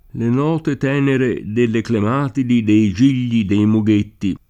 clematide [klem#tide] (antiq. clematite [klemat&te]) s. f. (bot.) — es.: Eran templi d’erba e d’ellera, Gallerie di clematiti [$ran t$mpli d $rba e dd %llera, galler&e di klemat&ti] (E. Praga); le note tenere delle clemàtidi, dei gigli, dei mughetti [